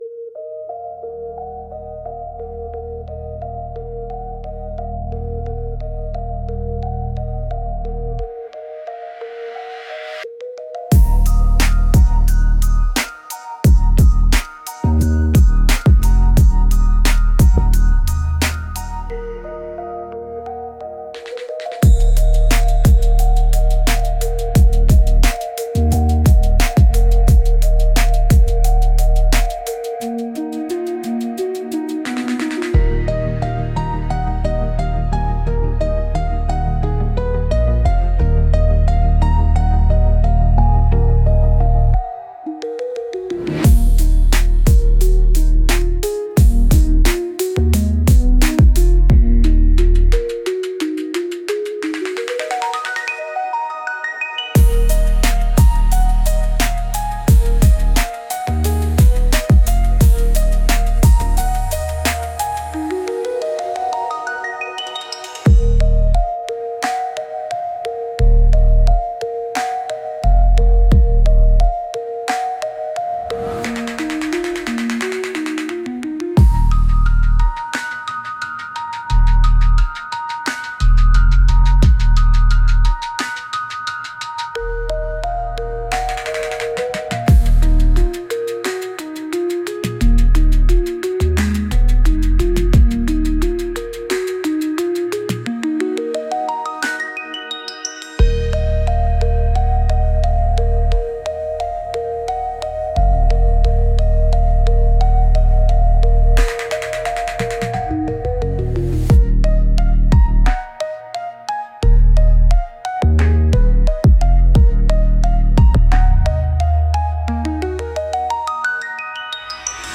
Instrumental - Real Liberty Media Dot XYZ- 2.47.mp3